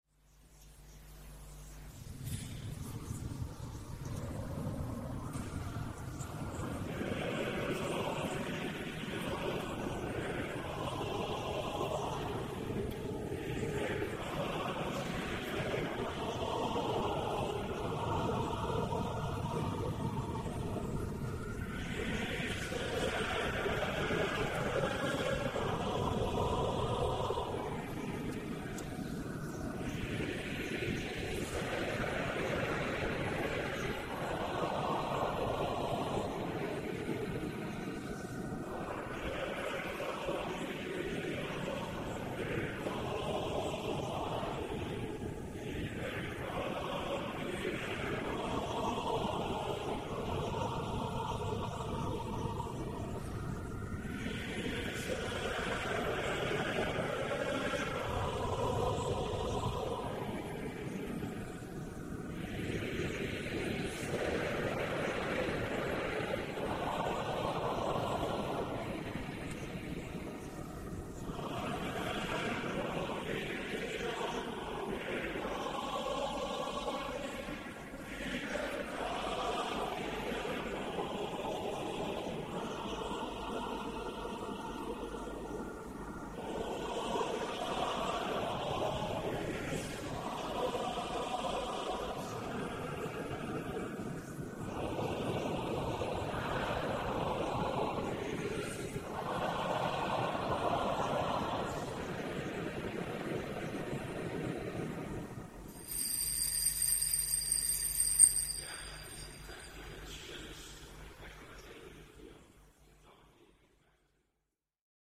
SCHOLA CANTORUM Sedico (Belluno)
Agordo 10_06_2012